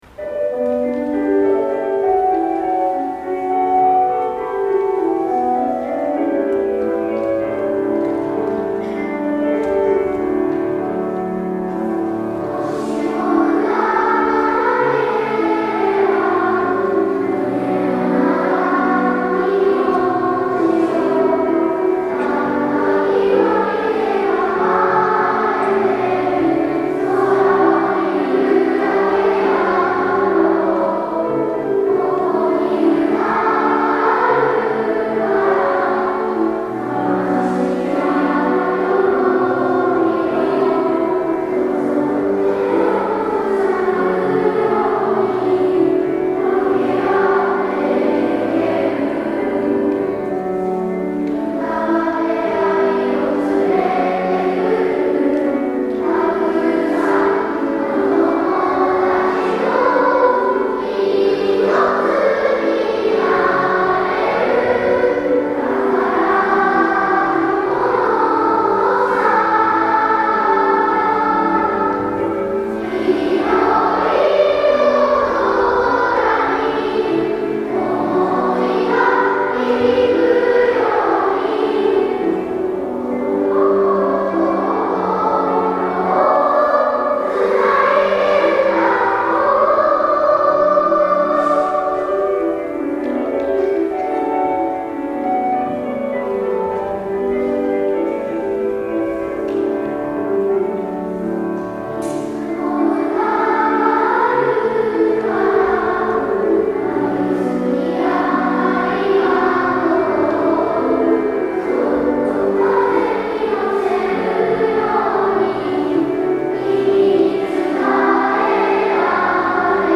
最後に全校合唱
今回は、１・２年、3・4年、5・6年と２学年ごとに集まり、合唱したものを編集し一つにつなぎました。
実際にここにいるわけではないですが、みんなで一緒に歌っているような、そして心が一つになったような一体感があり、あったかい空気に包まれました。